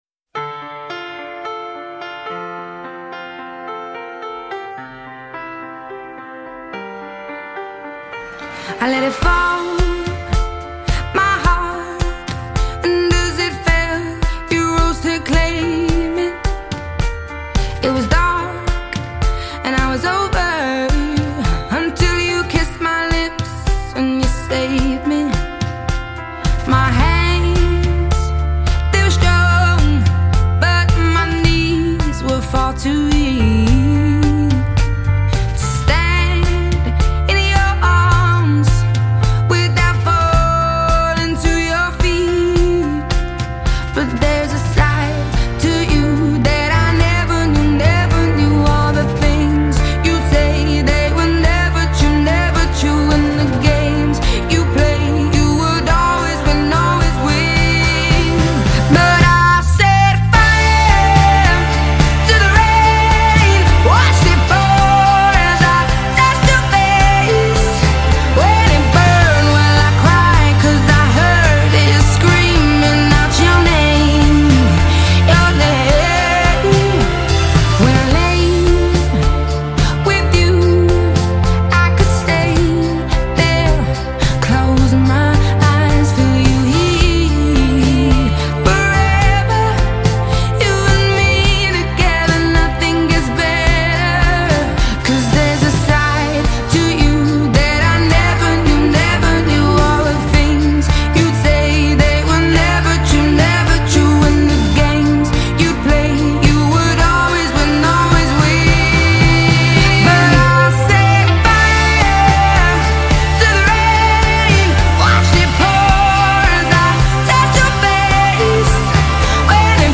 With nice vocals and high instrumental equipments